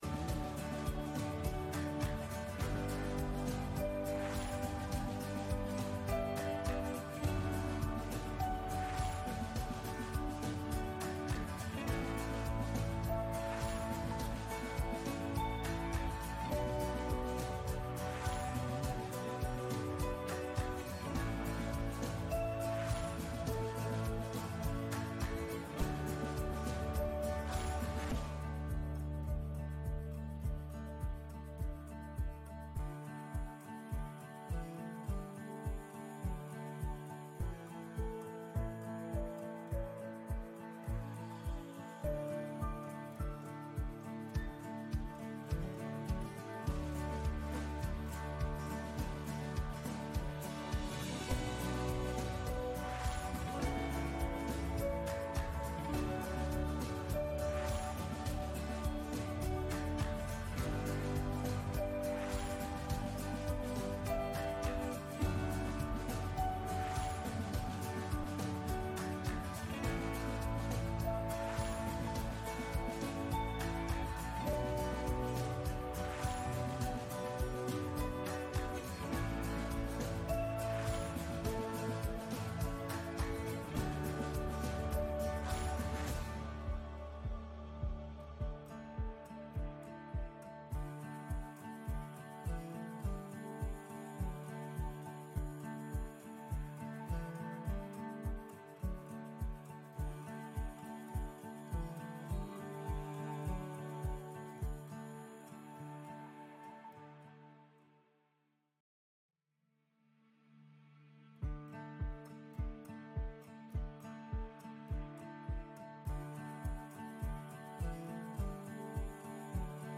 Gottesdienst am 15. September 2024 aus der Christuskirche Altona on 15-Sep-24-09:14:22
Videos und Livestreams aus der Christuskirche Hamburg Altona (Baptisten)